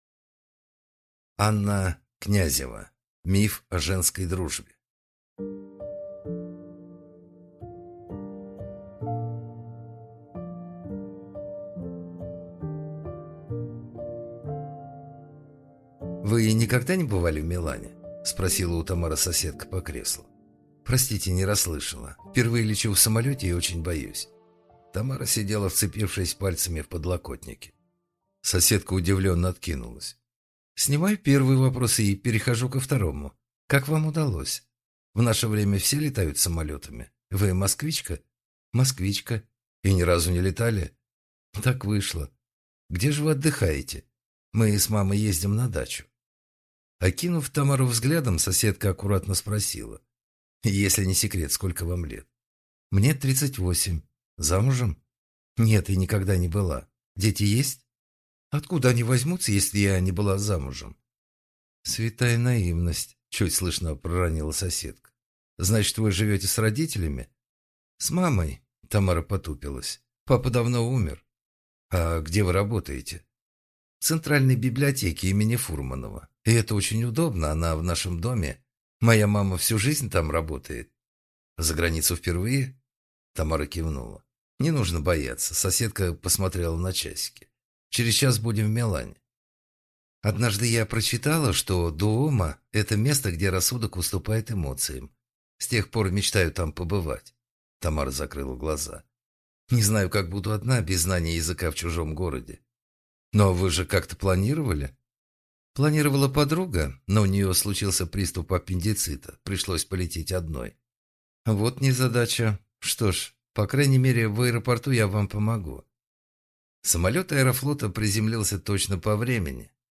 Аудиокнига Миф о женской дружбе | Библиотека аудиокниг
Прослушать и бесплатно скачать фрагмент аудиокниги